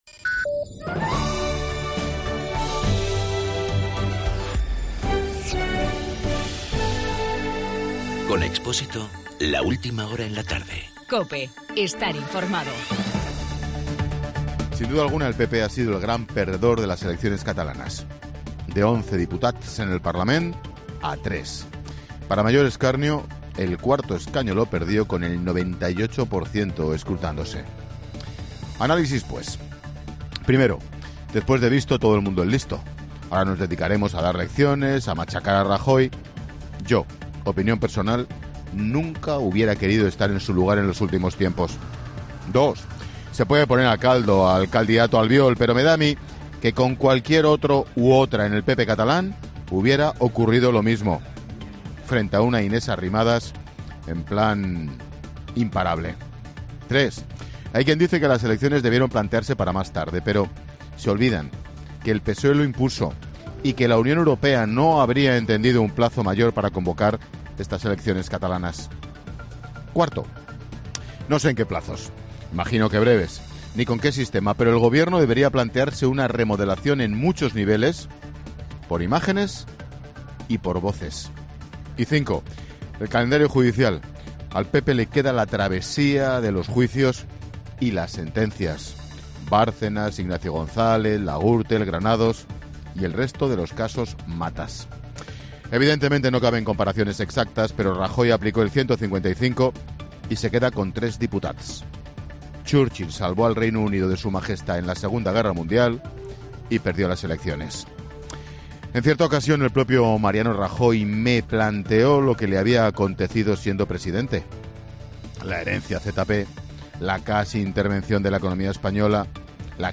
AUDIO: El comentario de Ángel Expósito desde Barcelona después de las elecciones catalanas.